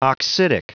Prononciation du mot oxidic en anglais (fichier audio)
Prononciation du mot : oxidic